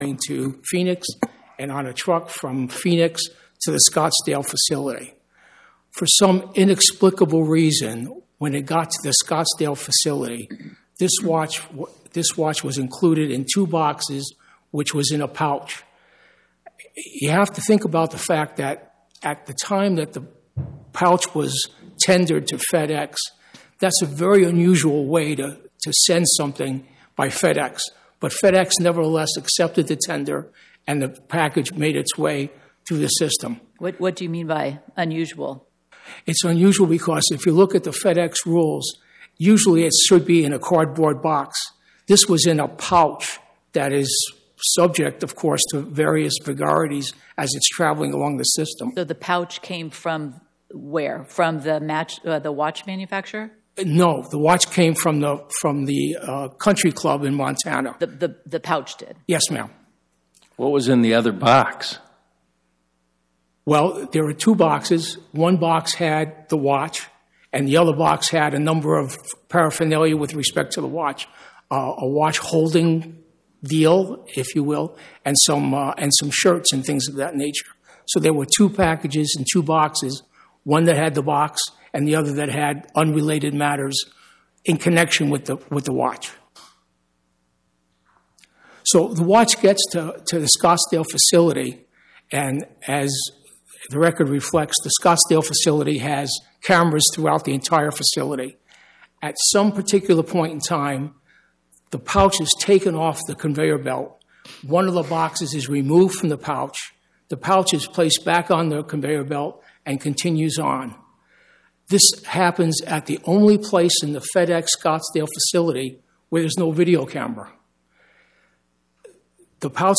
Oral argument argued before the Eighth Circuit U.S. Court of Appeals on or about 02/10/2026